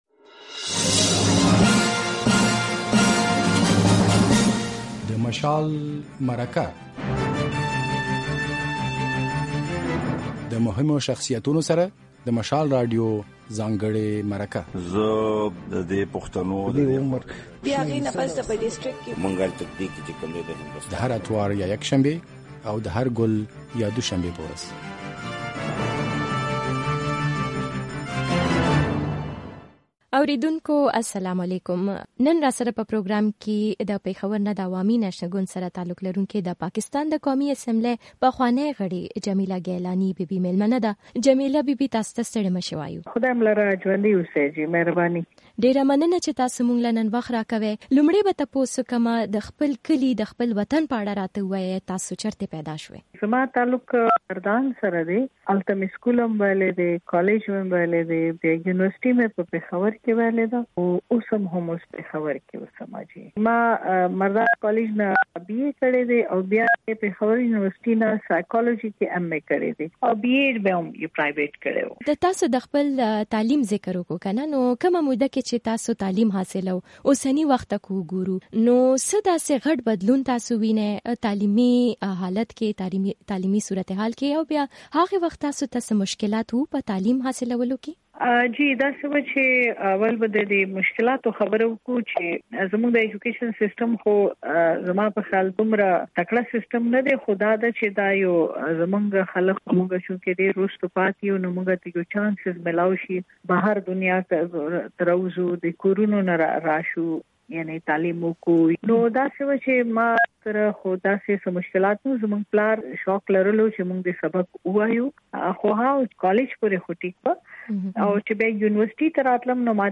له جمیلې ګېلانۍ سره د مشال مرکه